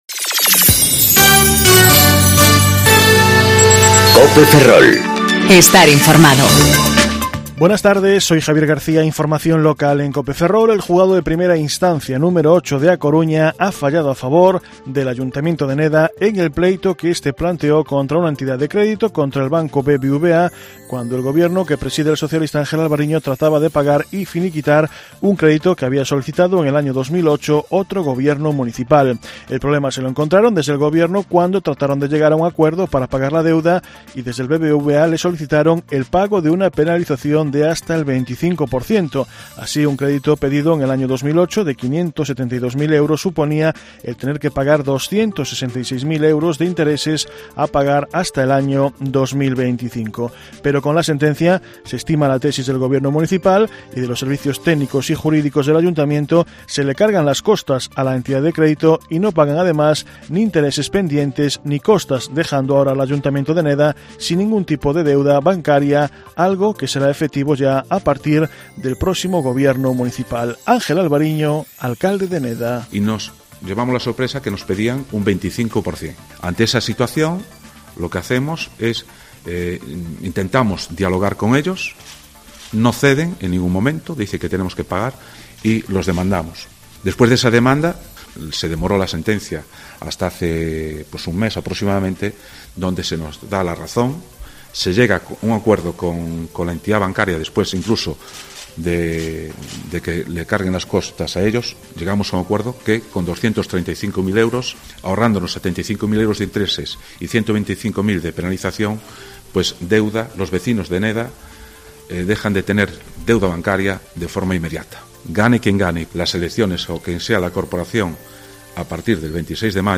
Informativo Mediodía Cope Ferrol 30/04/2019 (De 14,20 a 14,30 horas)